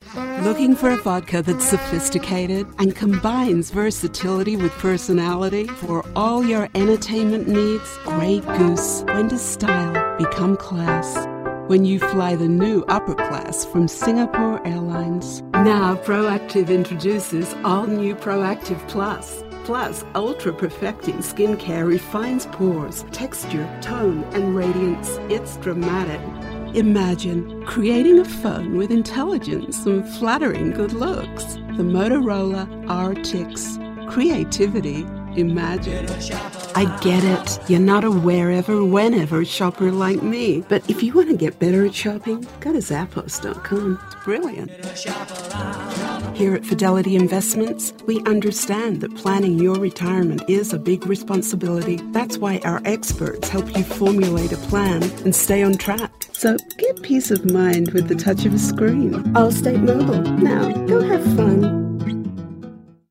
Voiceover actor working in British and Global Mid-Atlantic English from Los Angeles
Sprechprobe: Sonstiges (Muttersprache):
Warm, sophisticated and believable. Alto timbre.